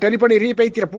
telephone iruhi peithira tamil Meme Sound Effect
telephone iruhi peithira tamil.mp3